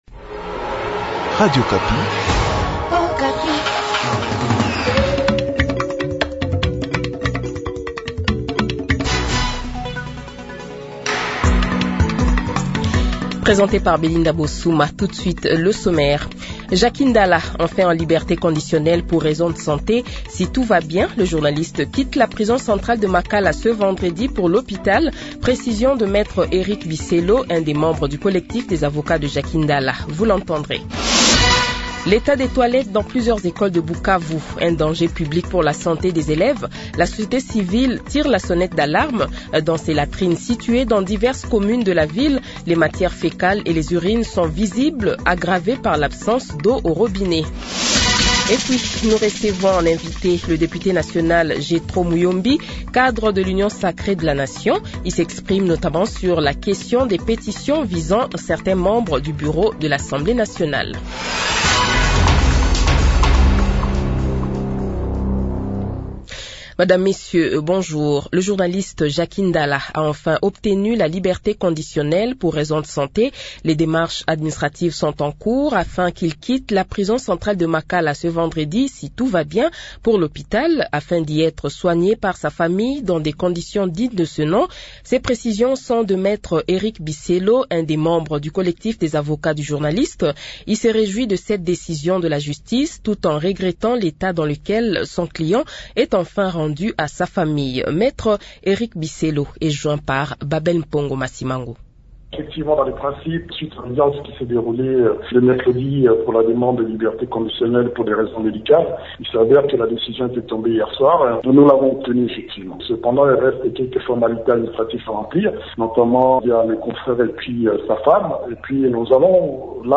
Le Journal de 12h, 19 Sptembre 2025 :